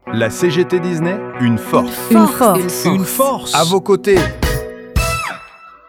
jingle.wav